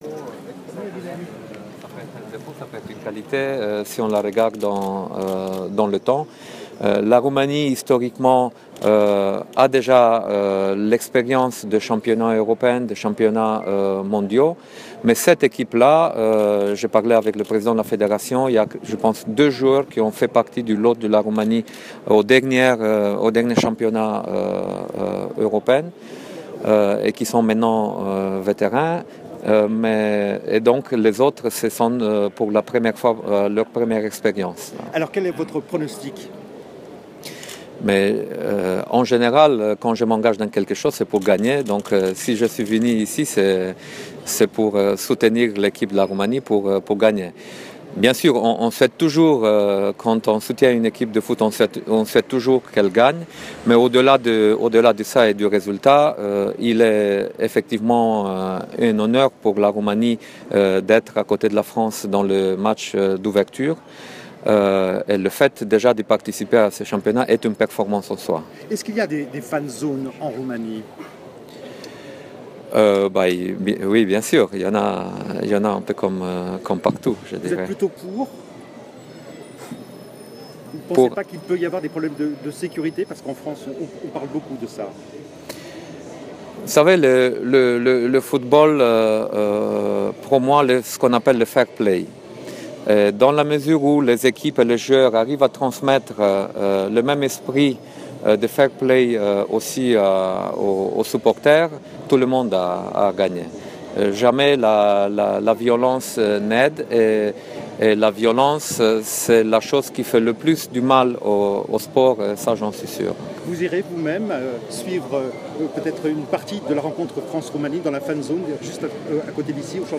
Premierul a răspuns întrebărilor ziariştilor francezi şi români.